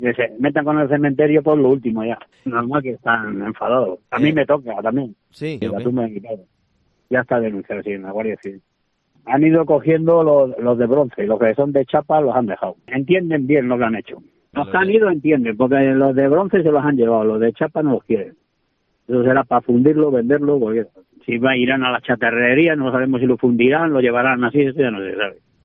AUDIO / El alcalde de La Hija de Dios, José Luis Muñoz Martin en COPE